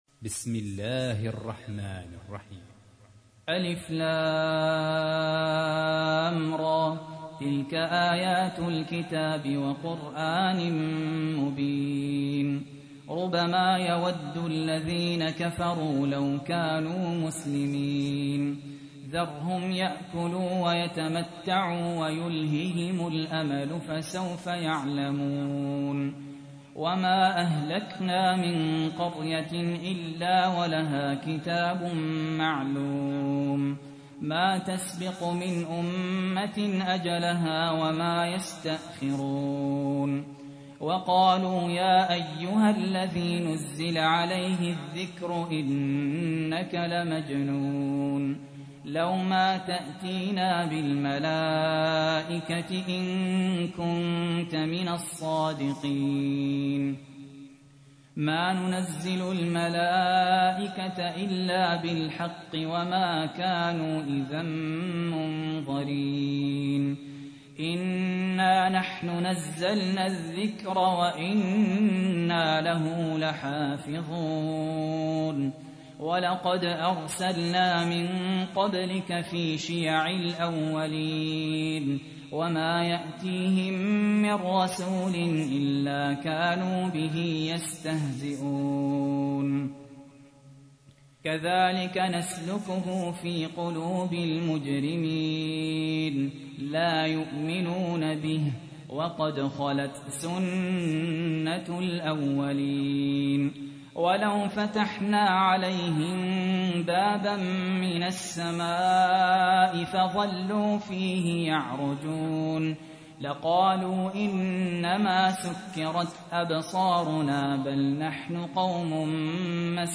تحميل : 15. سورة الحجر / القارئ سهل ياسين / القرآن الكريم / موقع يا حسين